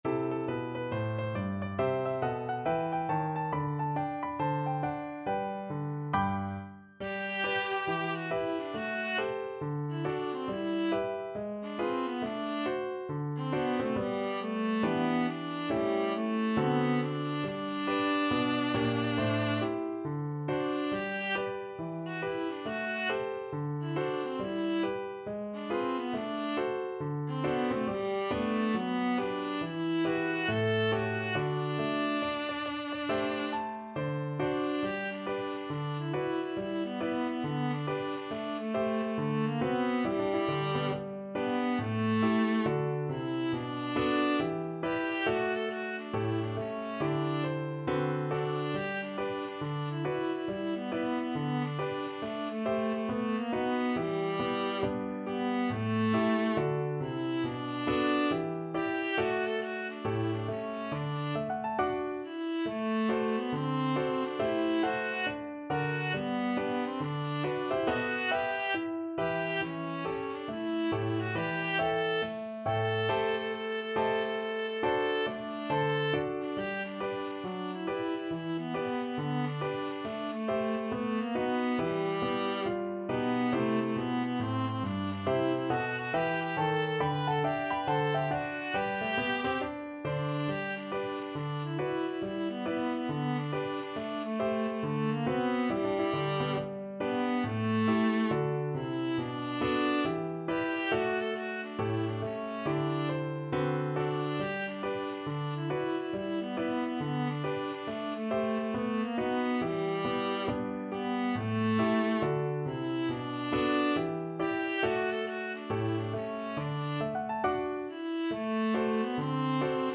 4/4 (View more 4/4 Music)
With a swing =c.69
Pop (View more Pop Viola Music)